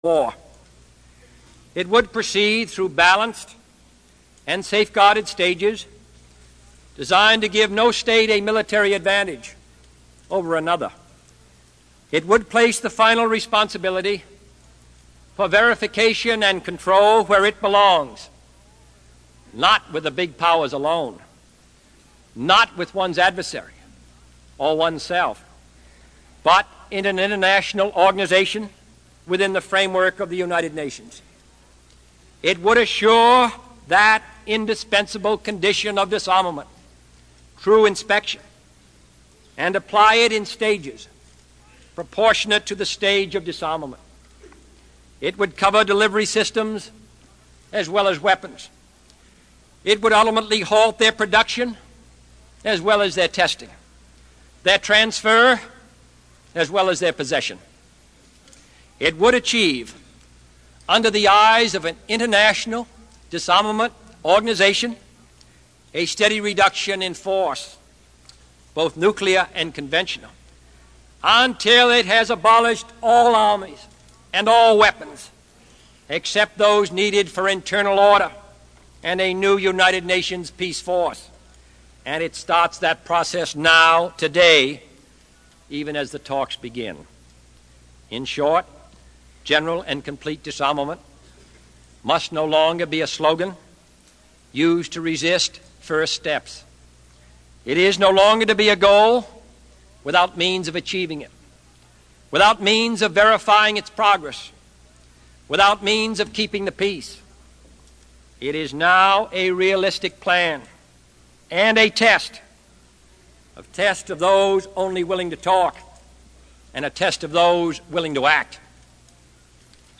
Tags: John F. Kennedy John F. Kennedy Address United Nations John F. Kennedy speech President